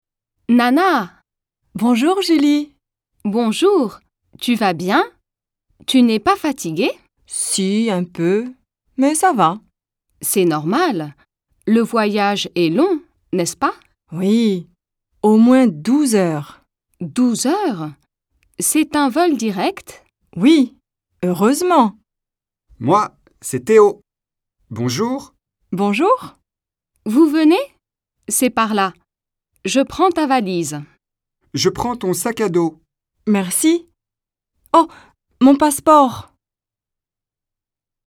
Here is the complete dialogue between Julie, Théo, and Nana: